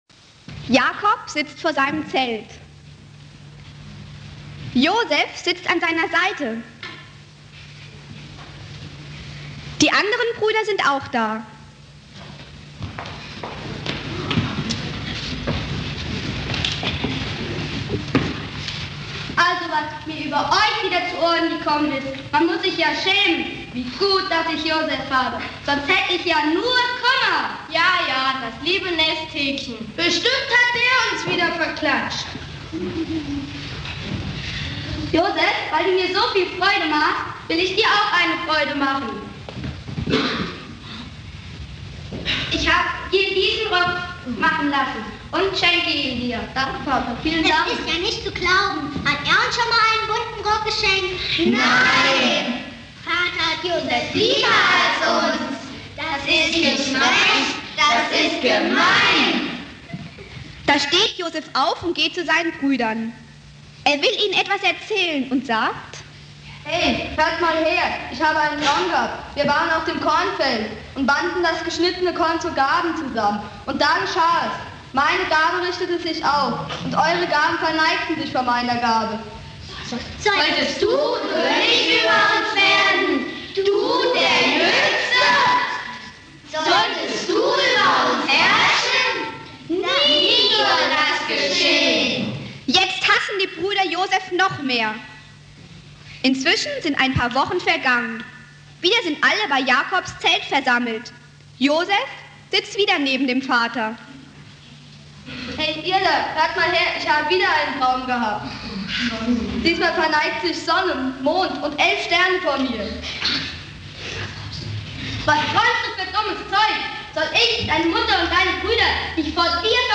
Predigt
Thema: Anspiel zu den biblischen Personen Josef, Samuel und David (Familiengottesdienst)